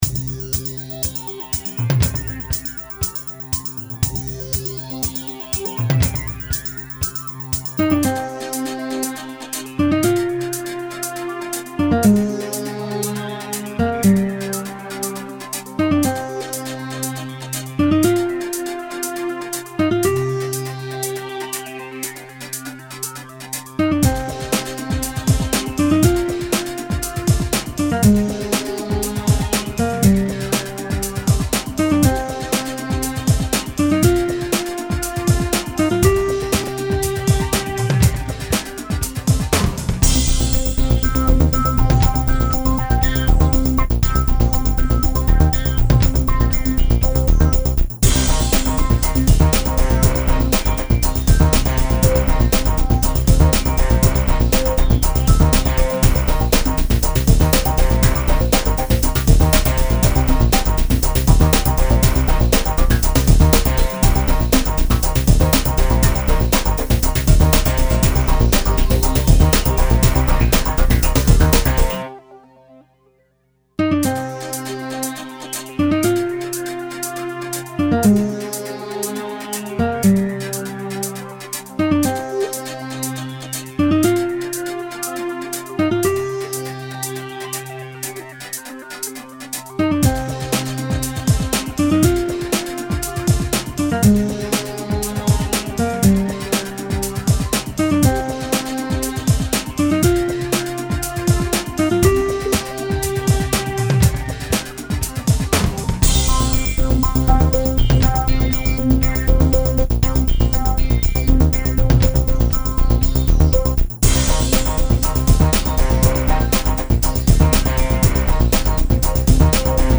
拉丁爵士乐